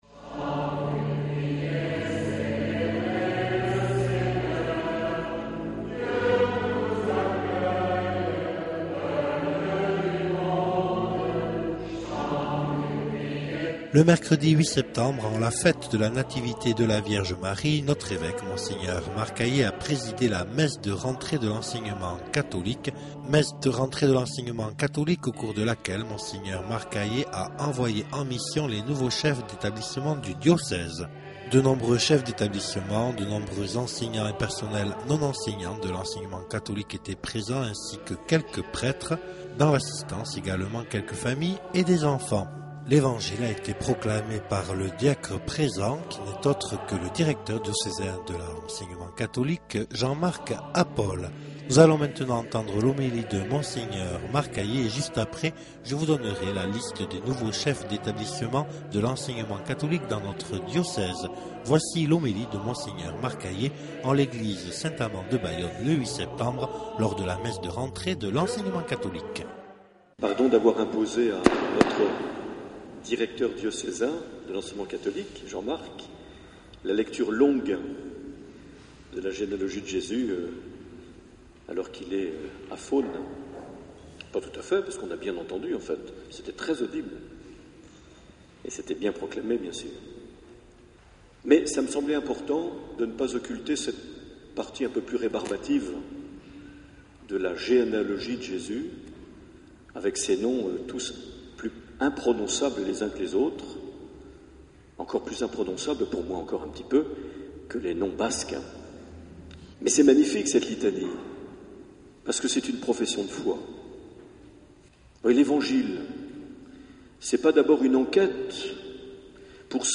08 septembre 2010 - Bayonne église Saint Amand - Nativité de la Vierge Marie Messe de rentrée de l’Enseignement Catholique
Accueil \ Emissions \ Vie de l’Eglise \ Evêque \ Les Homélies \ 08 septembre 2010 - Bayonne église Saint Amand - Nativité de la Vierge Marie (...)
Une émission présentée par Monseigneur Marc Aillet